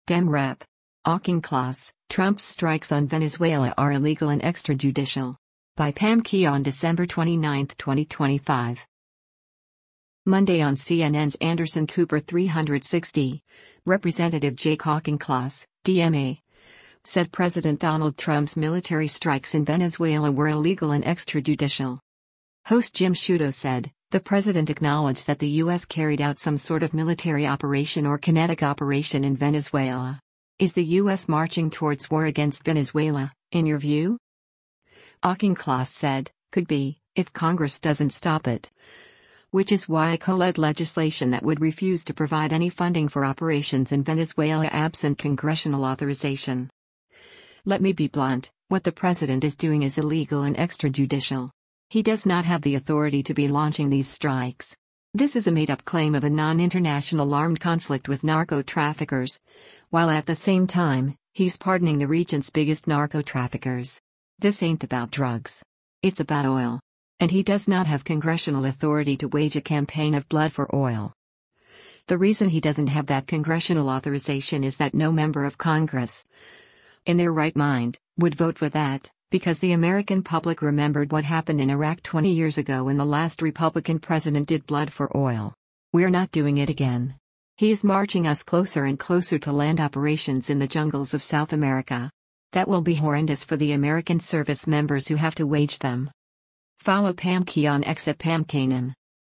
Monday on CNN’s “Anderson Cooper 360,” Rep. Jake Auchincloss (D-MA) said President Donald Trump’s military strikes in Venezuela were “illegal and extrajudicial.”
Host Jim Sciutto said, “The president acknowledged that the U.S. carried out some sort of military operation or kinetic operation in Venezuela. Is the U.S. marching towards war against Venezuela, in your view?”